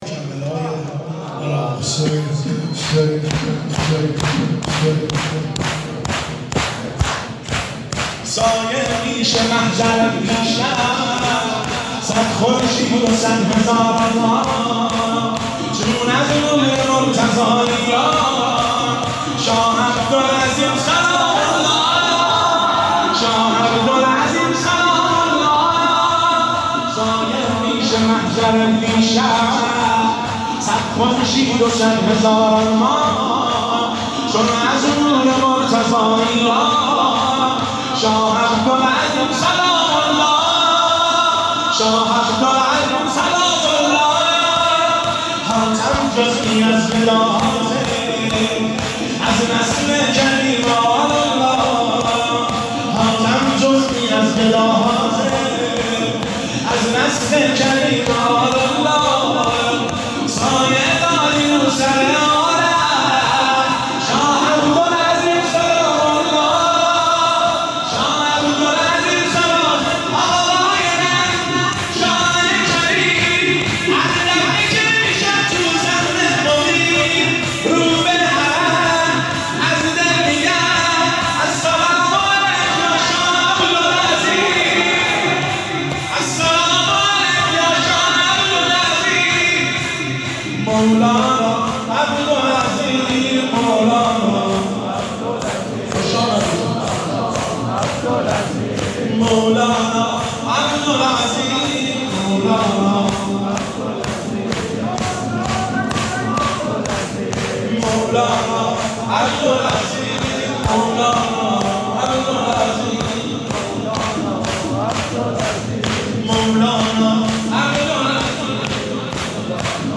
هیئت بین الحرمین طهران
دانلود (صوت ضبط شده)